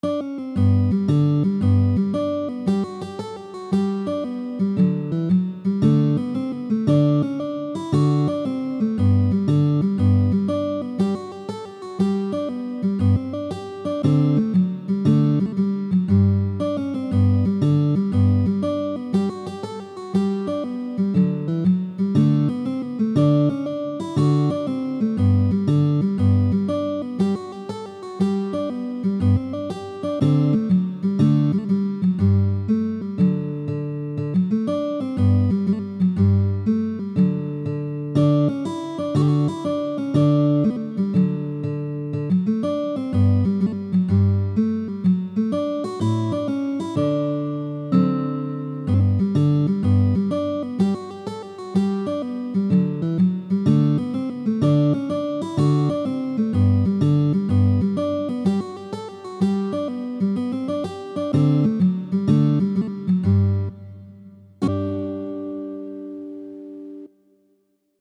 吉他指弹与原曲对比 谱内附带：GTP【配GP7,GP8版】、PDF曲谱【无GTP软件的请下载PDF版】